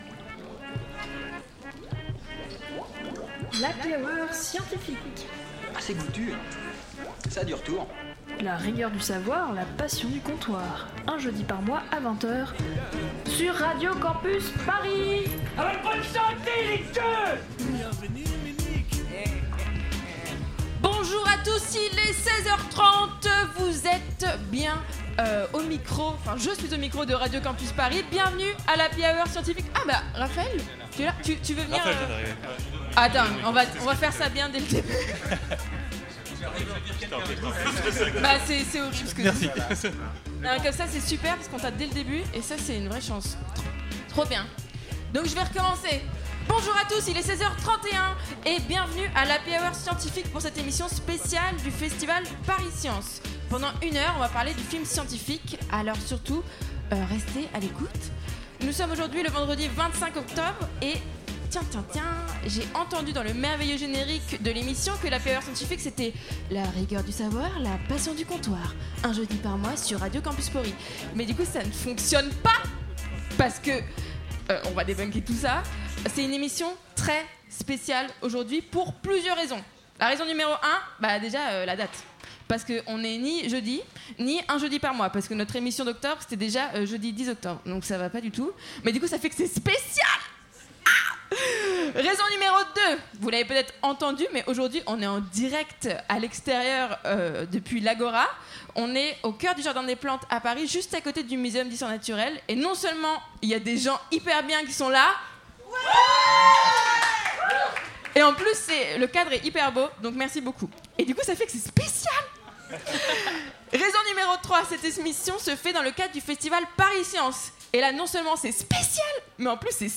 Nous avons enregistré cette émission spéciale en public, au cœur du Jardin des Plantes à Paris.